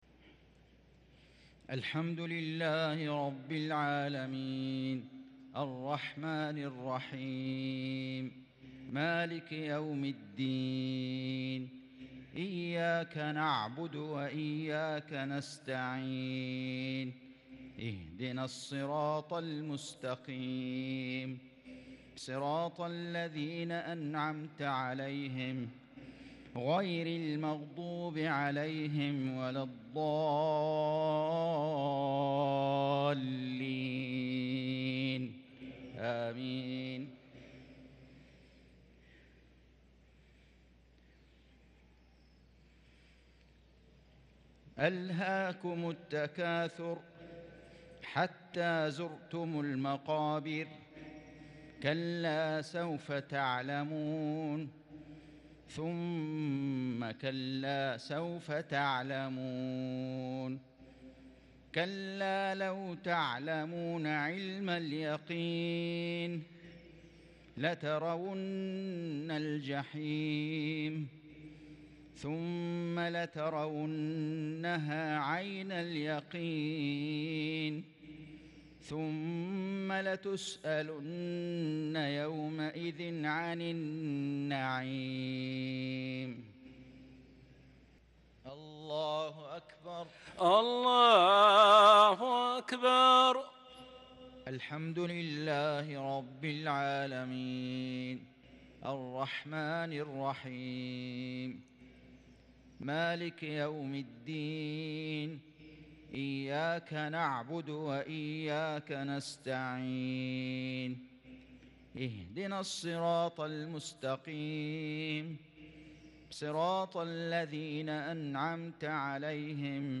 صلاة المغرب